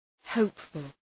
Shkrimi fonetik {‘həʋpfəl}